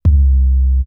JJCustom808s (14).wav